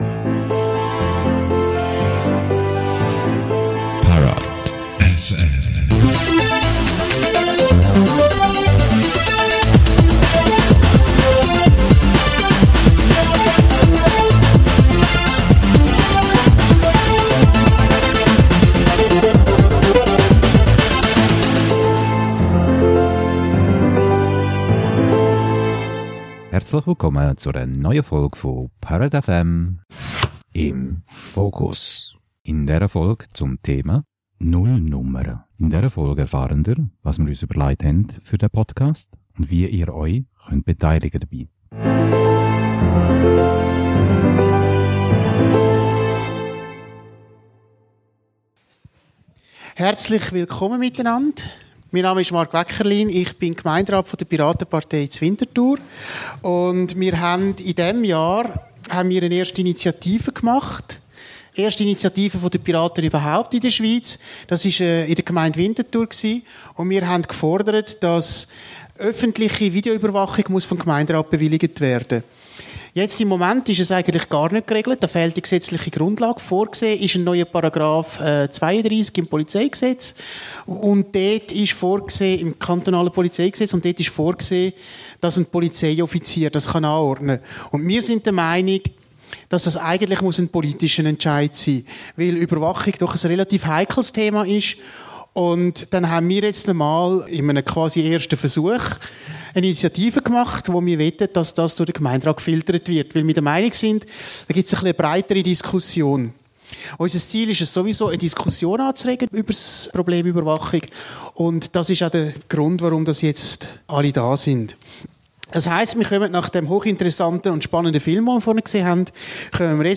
Im Fokus - Podiumsdiskussion Überwachung vom 22. September 2011